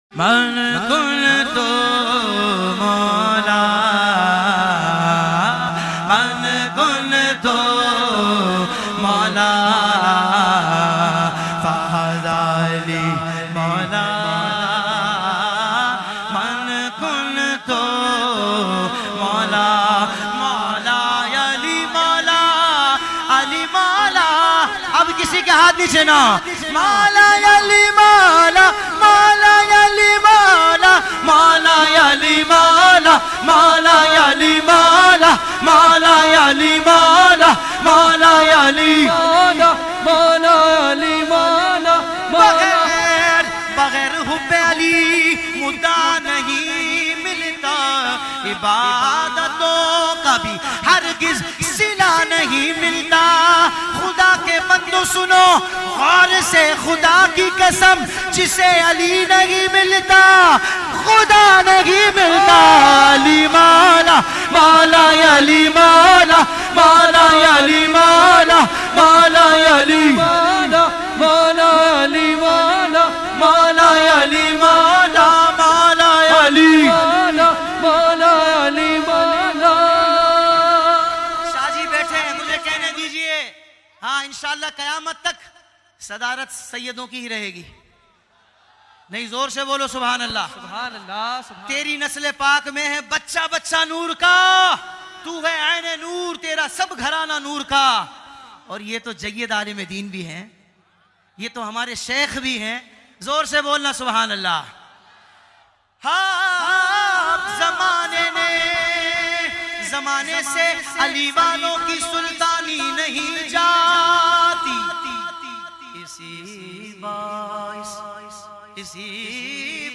man-kunto-maula-manqabat-maula-ali.mp3